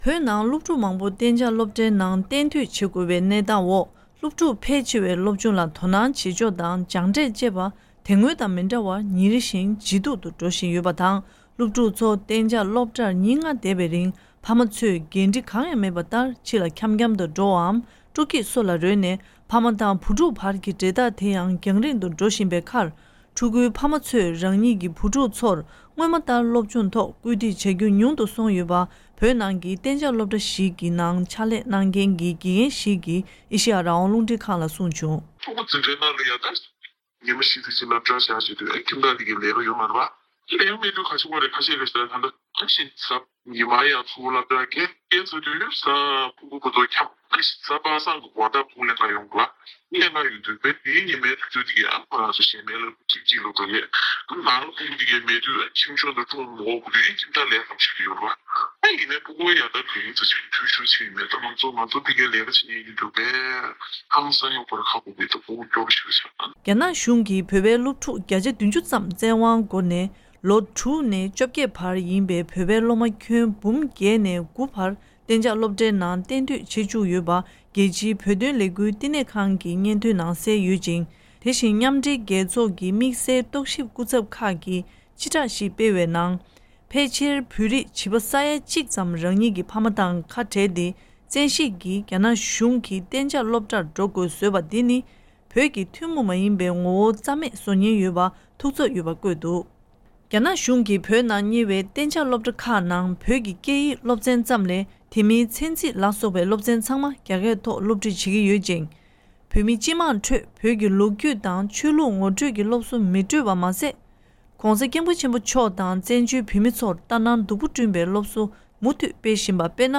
དཔྱད་གཏམ་ཕྱོགས་སྒྲིག་ཞུས་པ་ཞིག་གསན་རོགས་ཞུ